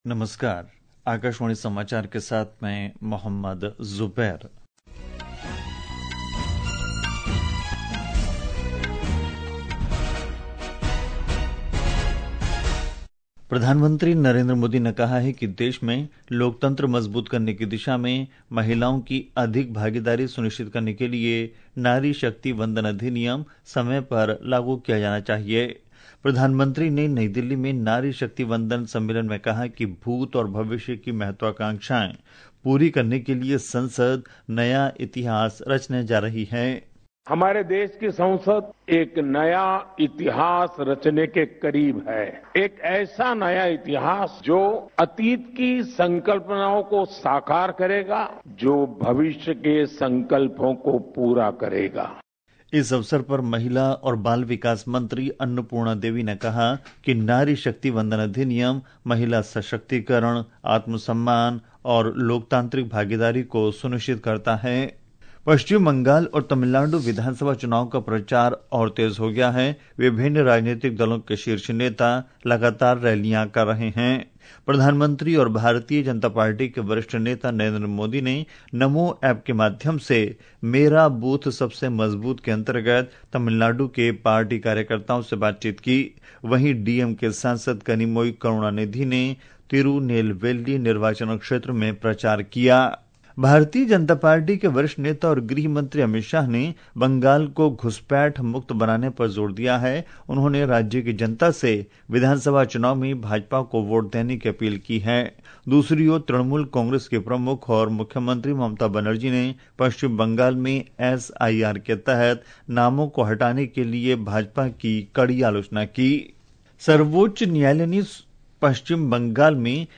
રાષ્ટ્રીય બુલેટિન
प्रति घंटा समाचार